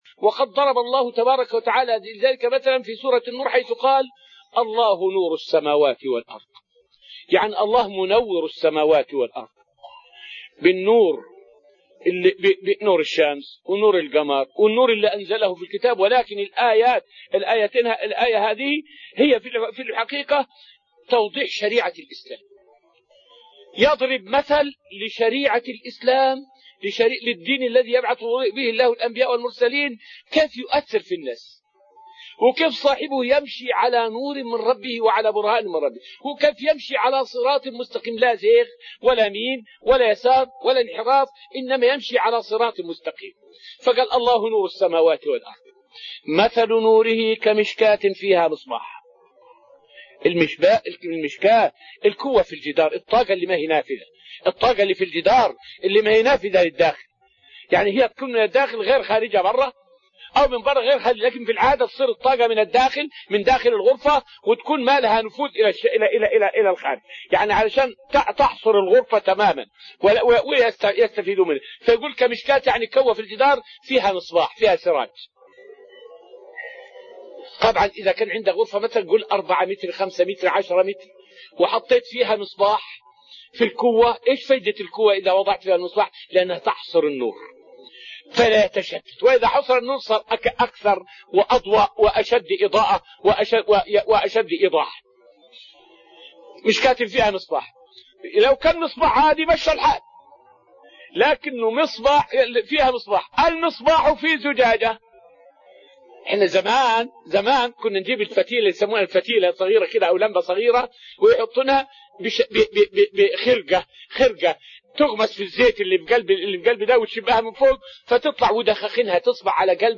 فائدة من الدرس الخامس من دروس تفسير سورة الذاريات والتي ألقيت في المسجد النبوي الشريف حول أن الإنسان مفطور على الإيمان بالله وإن جحد بلسانه.